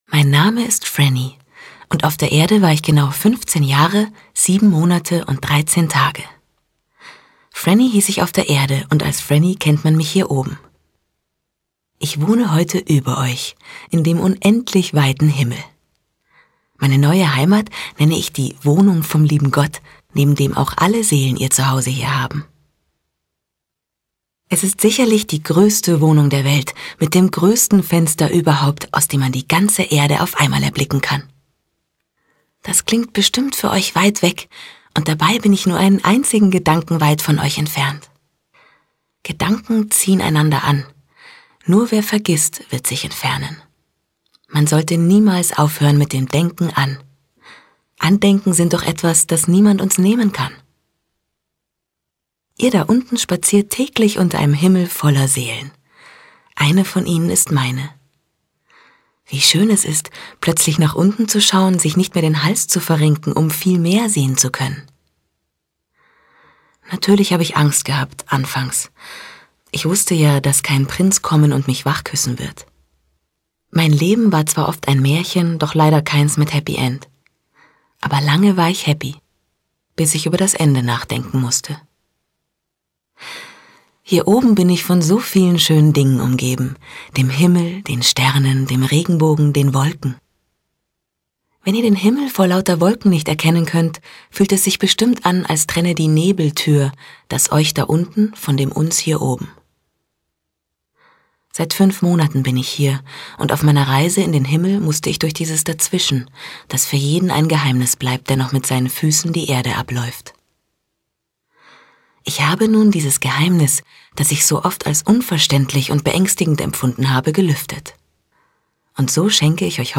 HÖRBÜCHER